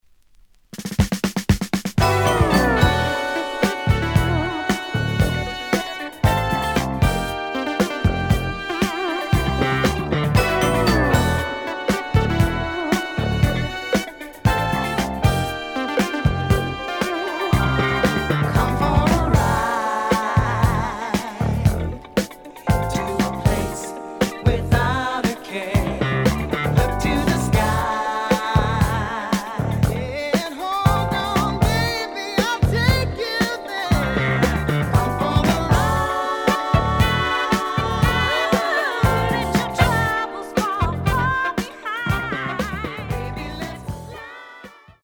The audio sample is recorded from the actual item.
●Genre: Disco